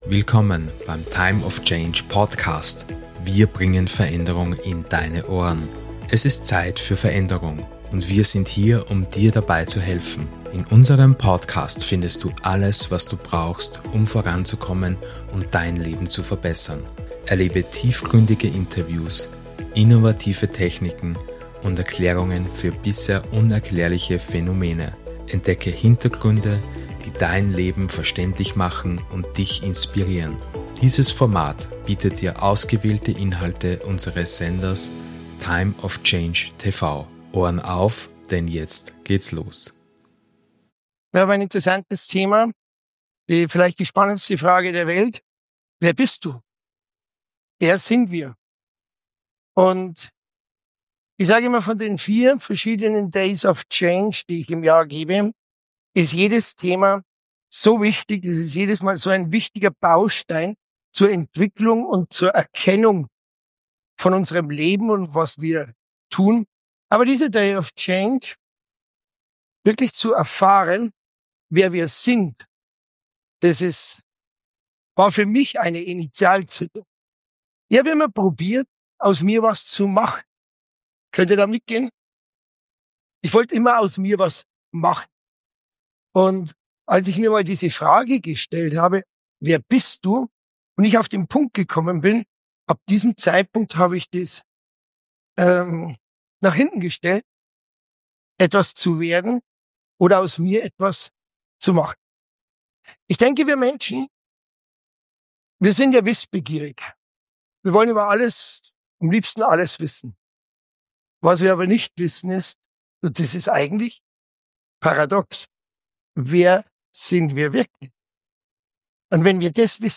Beschreibung vor 1 Jahr Entdecke in diesem kraftvollen Seminarauszug aus der Days of Change Reihe "Der Erschaffer" die fundamentale Frage: Wer bist Du?
Dieser Auszug aus den ersten zwei Stunden des transformativen Seminarwochenendes zeigt Dir, wie Du Dich von den Beschränkungen Deines Unterbewusstseins befreien kannst. Lerne zu verstehen, weshalb Dein Unterbewusstsein nicht logisch denken kann und wie es dennoch Deine täglichen Entscheidungen und Handlungen maßgeblich beeinflusst.